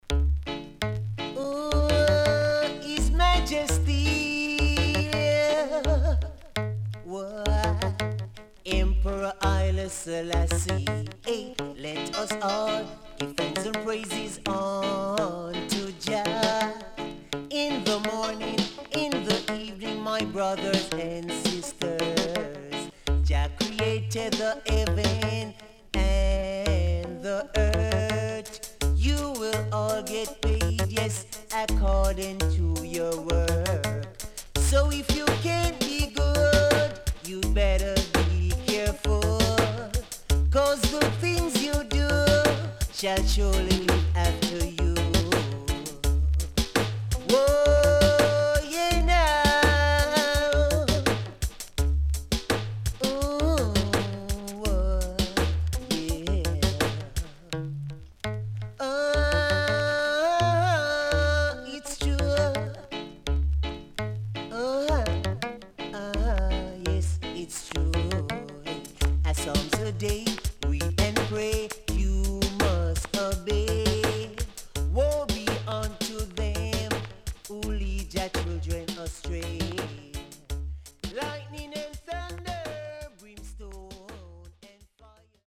HOME > DANCEHALL
Rare.Killer Digi Roots
SIDE A:少しチリノイズ、プチノイズ入ります。